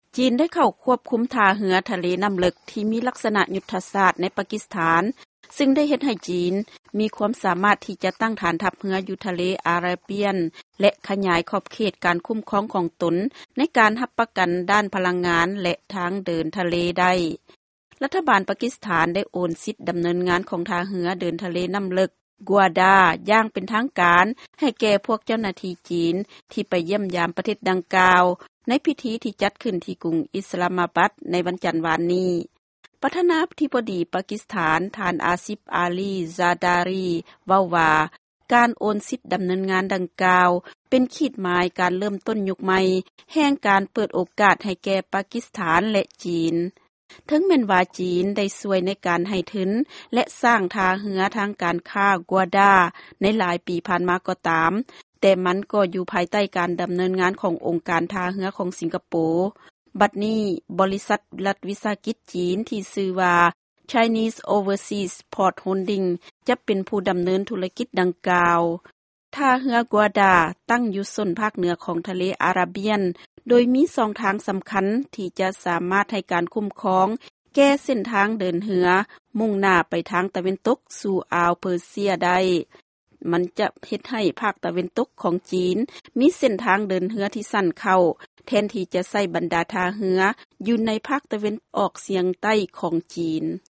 ຟັງຂ່າວກ່ຽວກັບຈີນແລະປາກິສຖານ